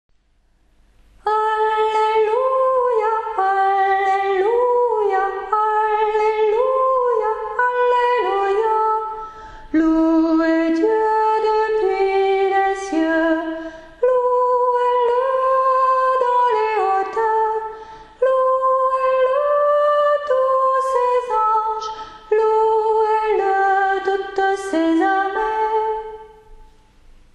Voix chantée (MP3)COUPLET/REFRAIN
SOPRANE